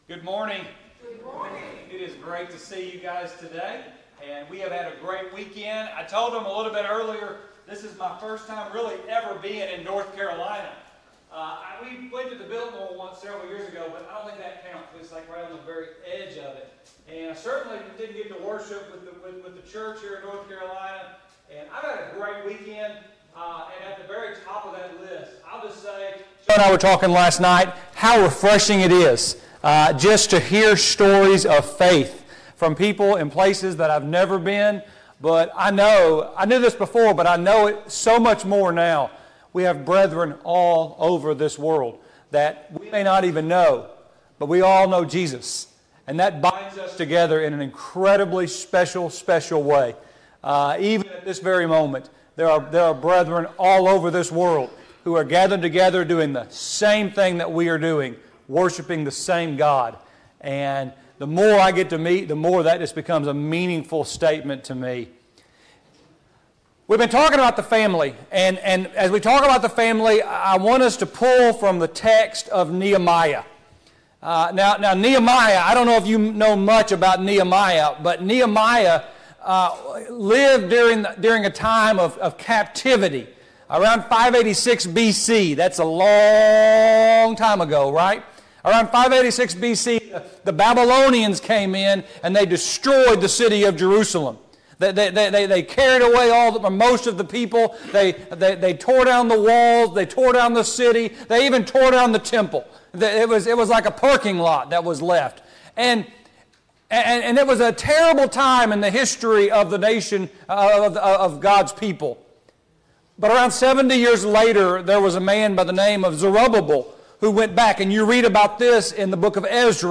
Nehemiah 4:14 Service Type: Gospel Meeting « 3.